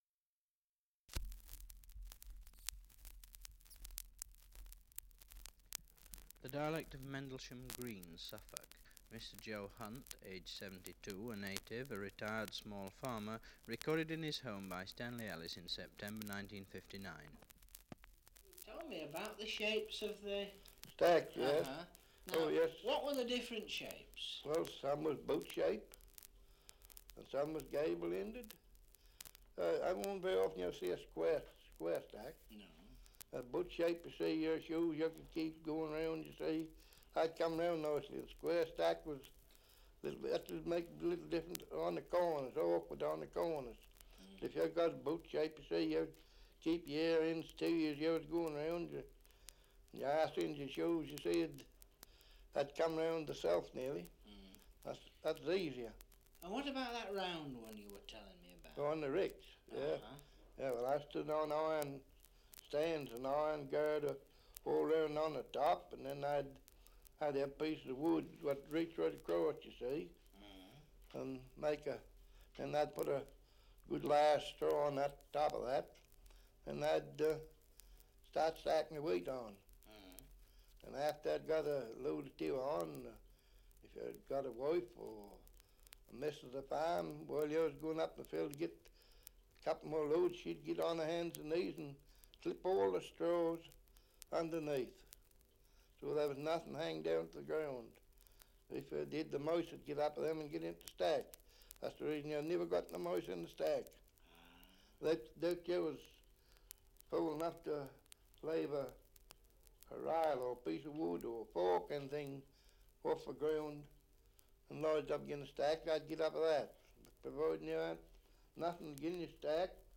Survey of English Dialects recording in Mendlesham, Suffolk
78 r.p.m., cellulose nitrate on aluminium